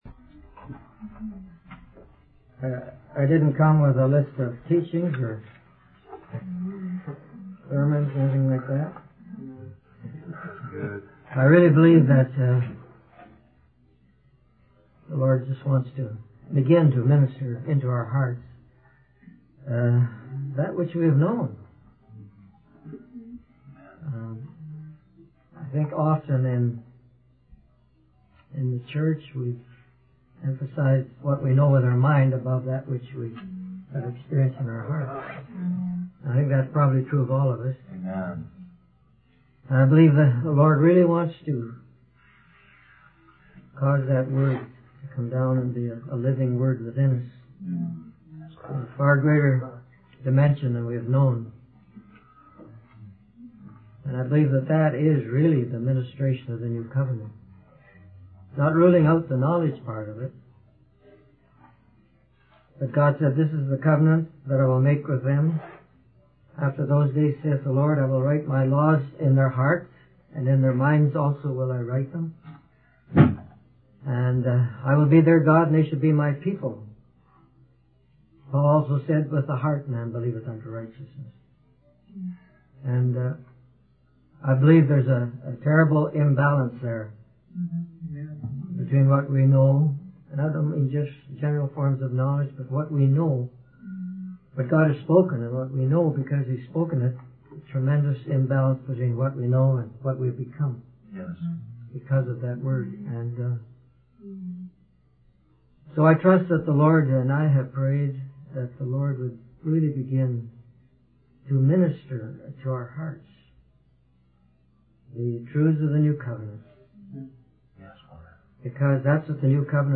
In this sermon, the speaker emphasizes the importance of staying focused and protecting the Lord's table. They encourage the audience to watch their mouths and speak in the spirit.
They remind the audience that God's timing may seem slow to us, but it is necessary for our hearts to be prepared for His glory. The sermon concludes with an invitation to fellowship and an opportunity to give towards the expenses of the week.